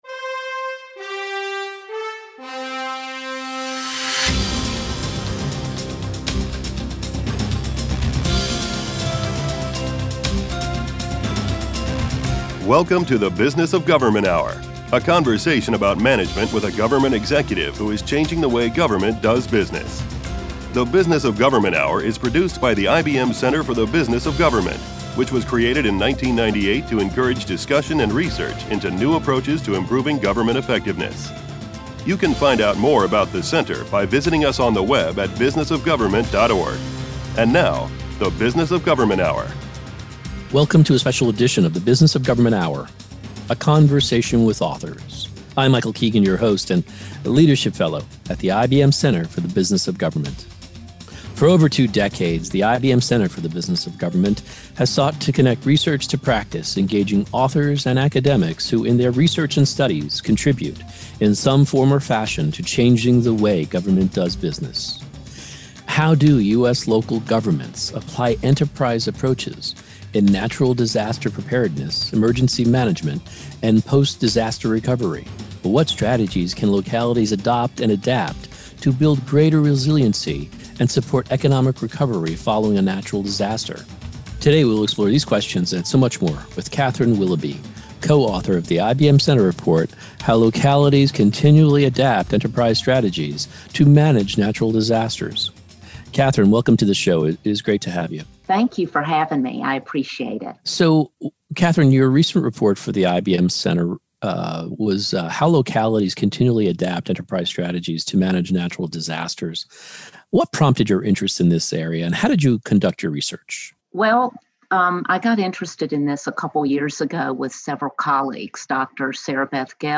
Interviews | IBM Center for The Business of Government